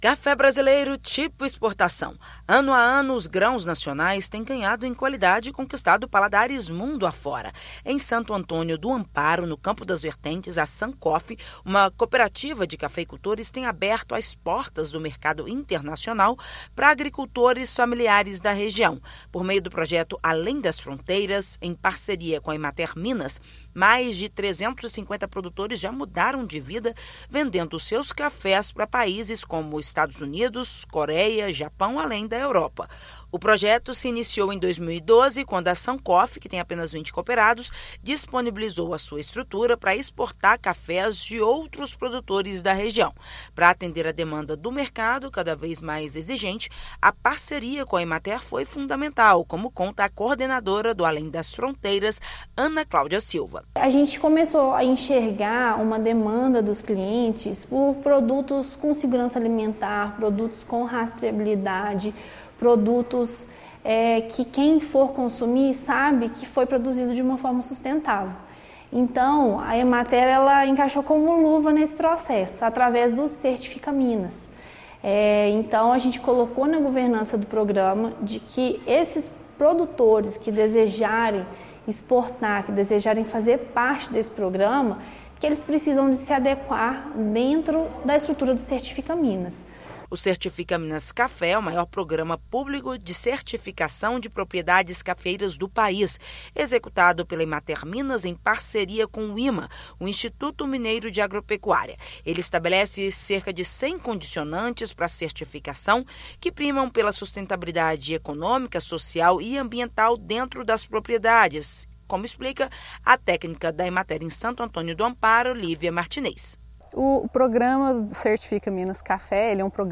Mais de 350 produtores já mudaram de vida, vendendo seus cafés para países como Estados Unidos, Coreia, Japão, além da Europa. Ouça matéria de rádio.